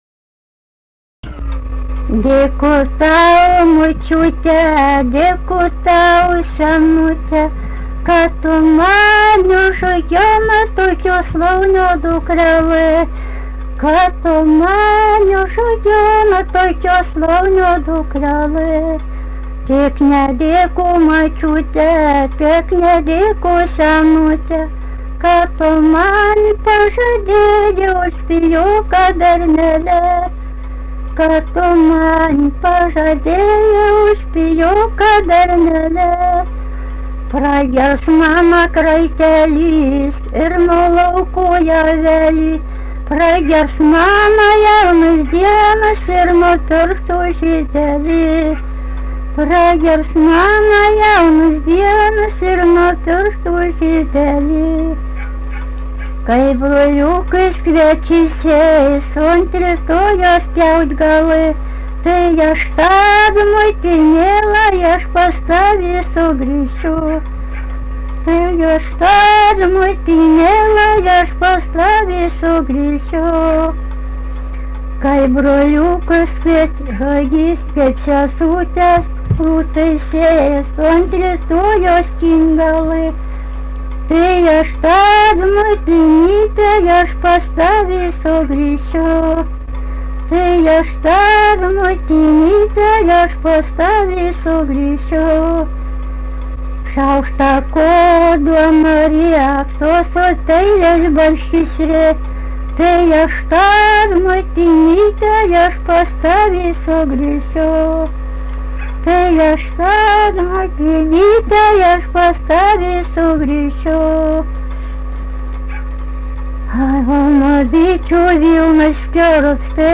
daina, vestuvių
Birželiai
vokalinis